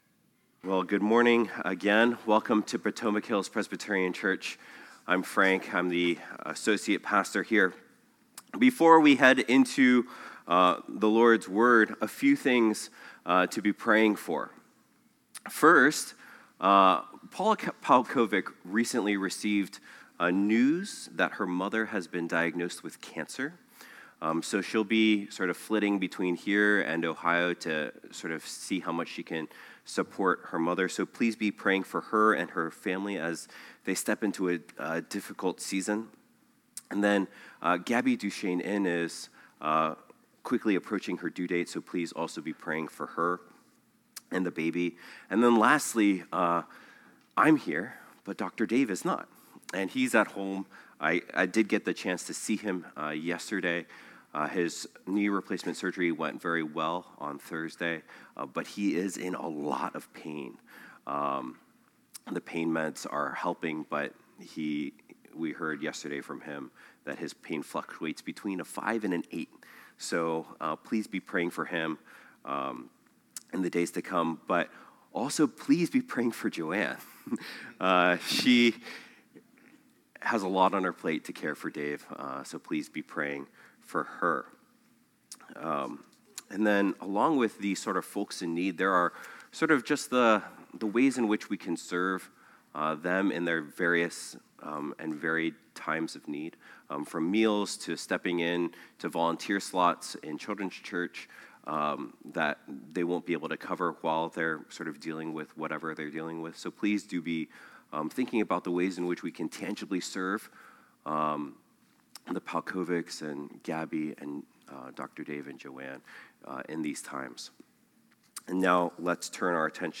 phpc-worship-service-5-25-25.mp3